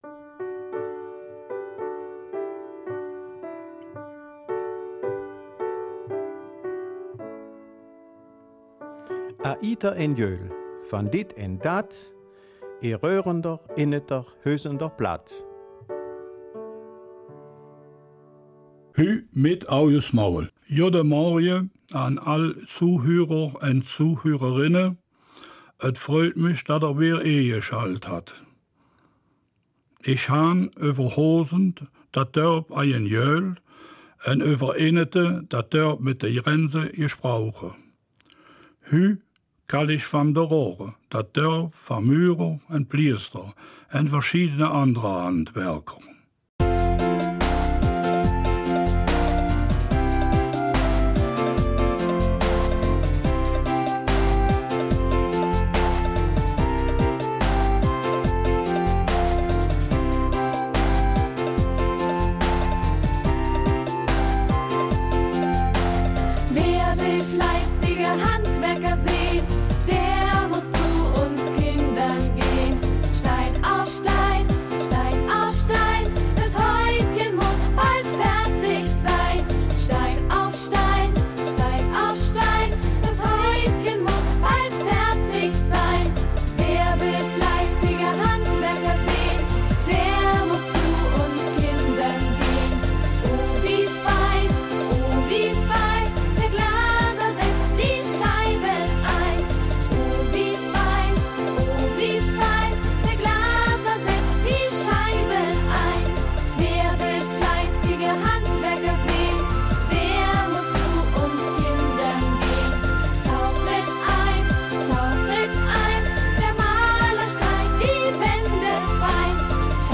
Raerener Mundart am 30. Juli